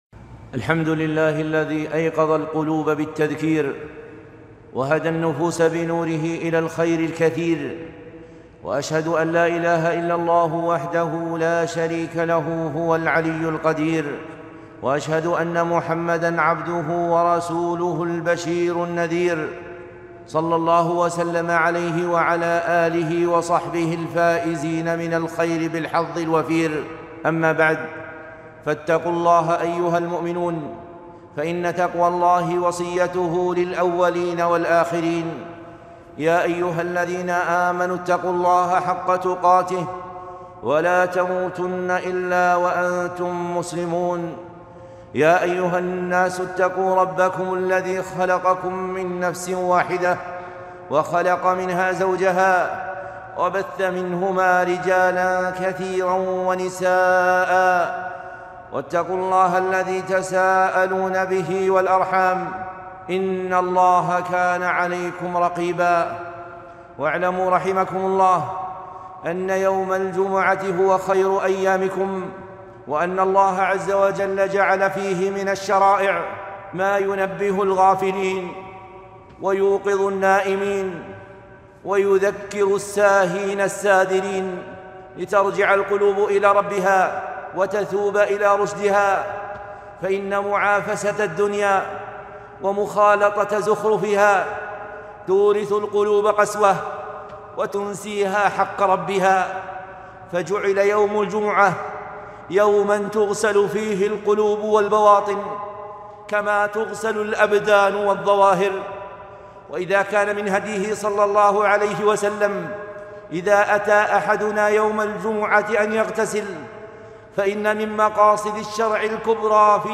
خطبة مؤثرة - موقظة القلوب في الجمعة